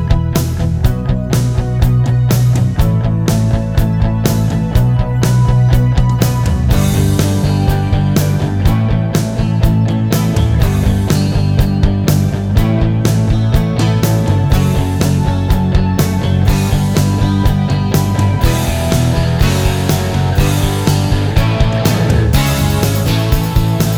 no Backing Vocals Pop (1980s) 4:07 Buy £1.50